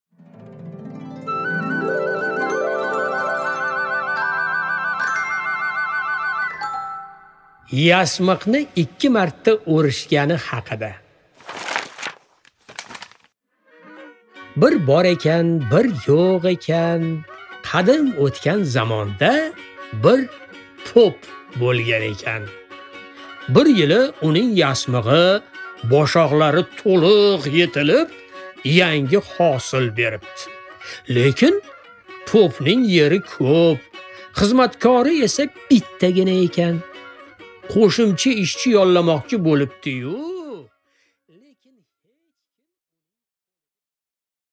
Аудиокнига Yasmiqni ikki marotaba o'rishgani haqida